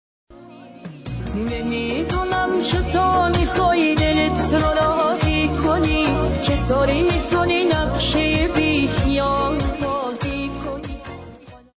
ساخته شده با هوش مصنوعی